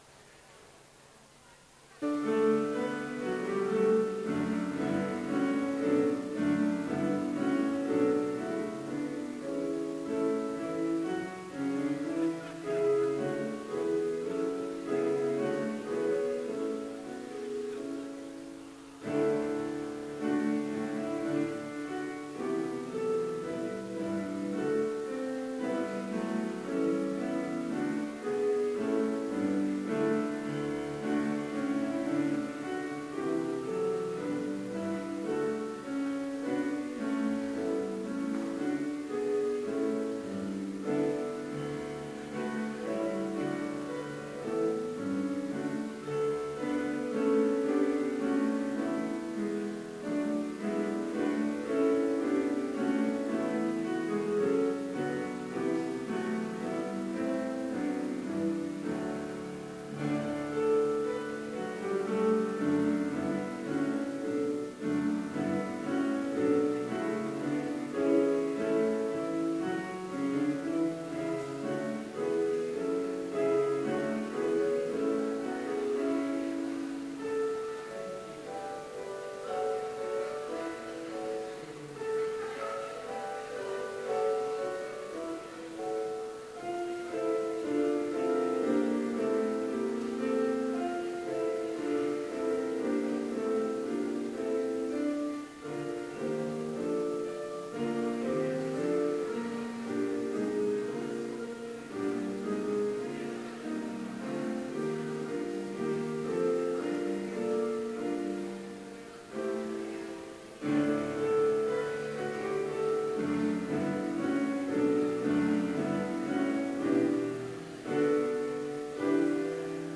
Complete service audio for Chapel - November 6, 2015
PSALM (sung by soloist with congregation joining on the refrain)